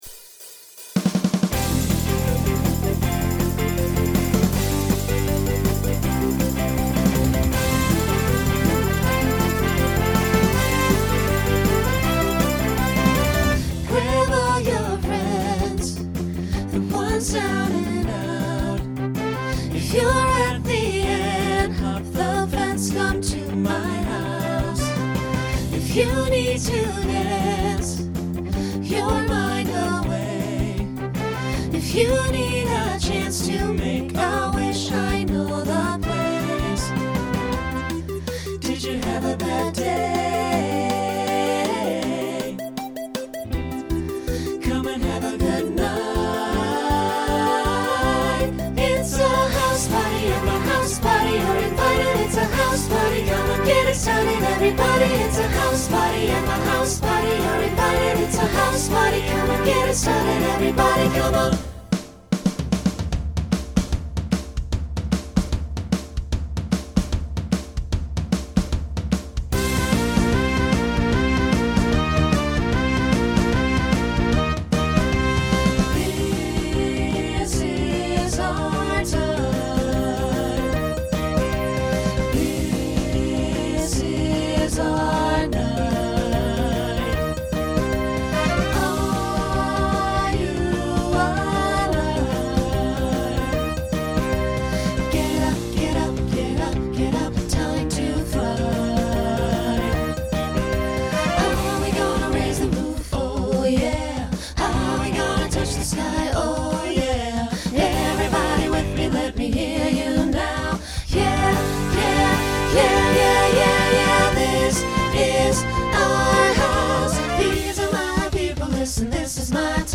Genre Rock , Swing/Jazz
Story/Theme Voicing SATB